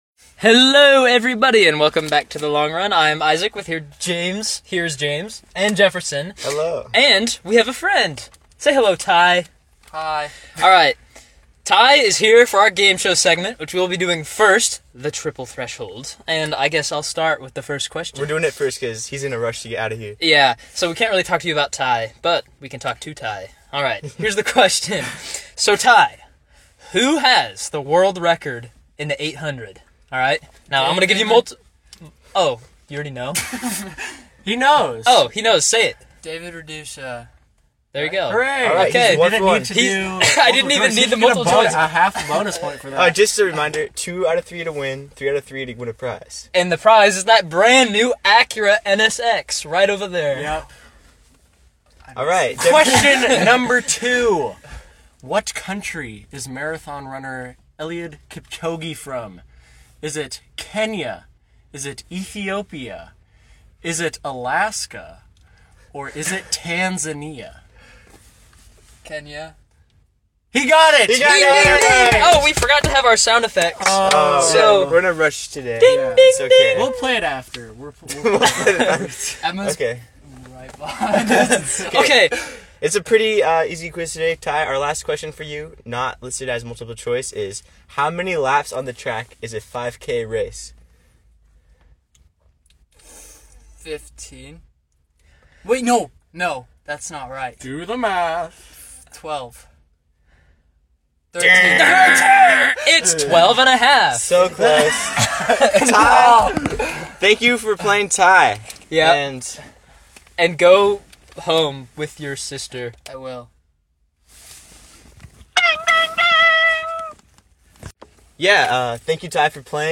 Play Rate Listened List Bookmark Get this podcast via API From The Podcast A fun trio from Washington yapping about the running world.